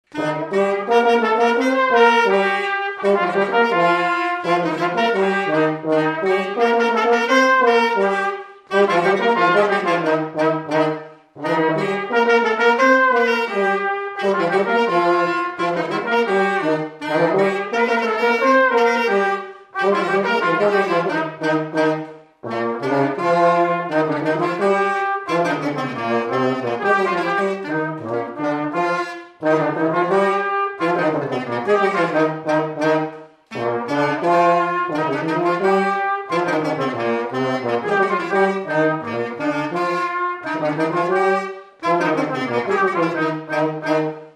Chants brefs - A danser
Résumé instrumental
danse : scottish
Pièce musicale inédite